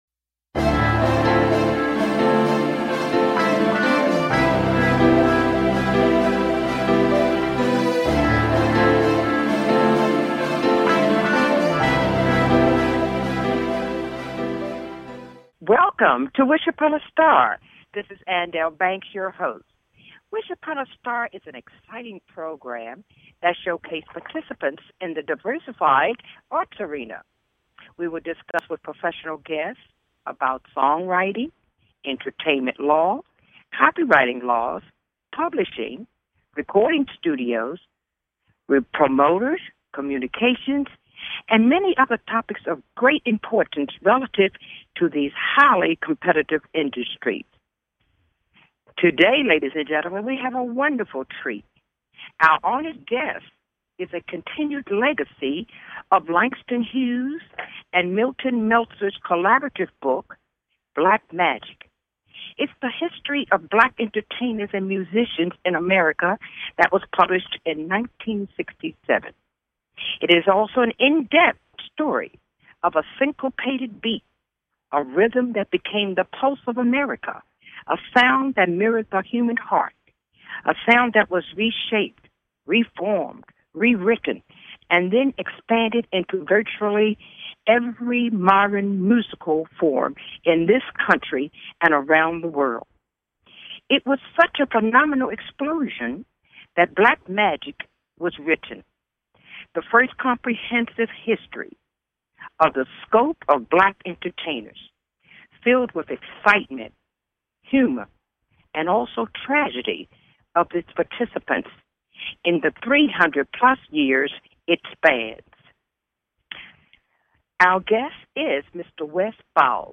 Conversations discussing the challenging elements of visual, musical, literary, painting, and the performing arts. We will interview professional guests in the fields of entertainment law, copyrighting, studio recording, songwriting, publishing, and other topics in these highly creative and challenging fields."